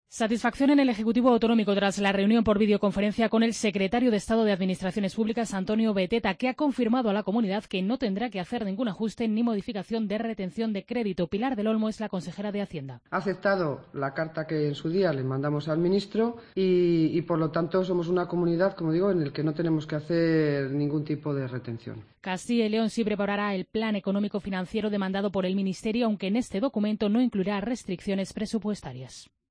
AUDIO: Hacienda libera a Castilla y León de hacer más recortes para ajustar déficit. Crónica